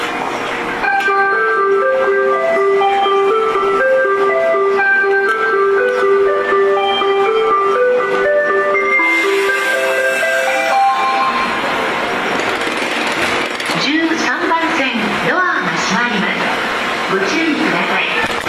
せせらぎ 周りが静かです。